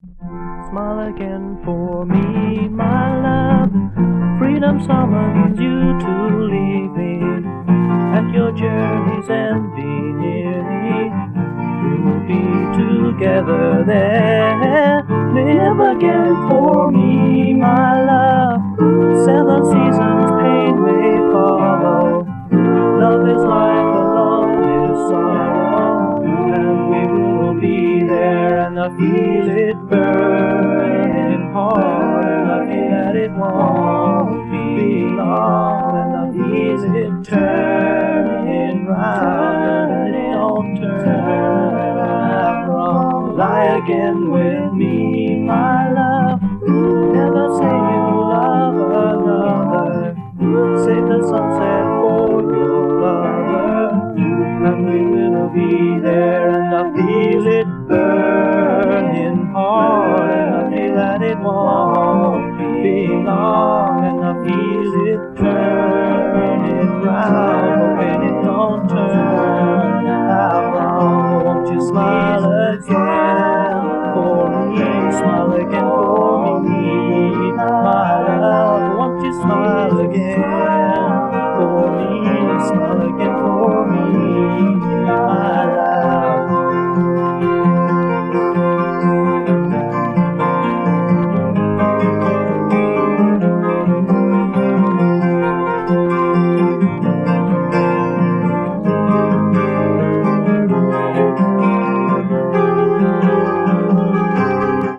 素朴でありながらもリリカルな風情もあり、そこはかとなく漂うアシッドなムードも素晴らしいですね！